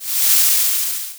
watershield.wav